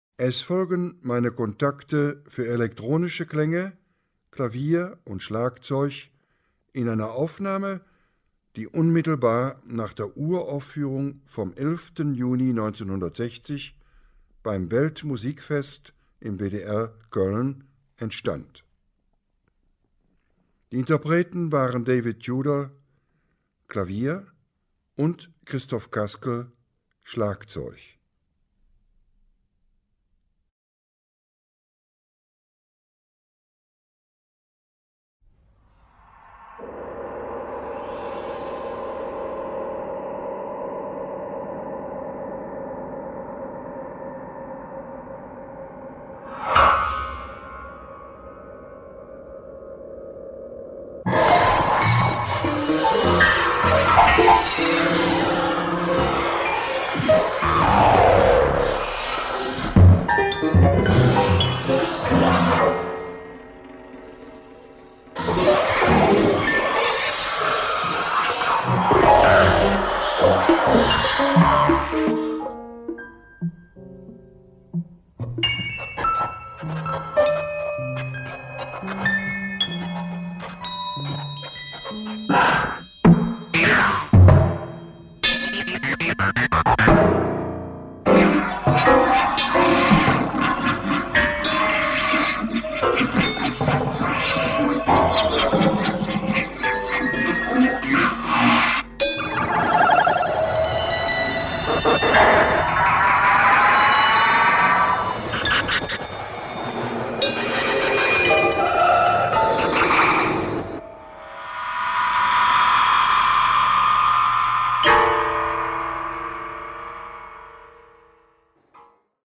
Vortrag / Lecture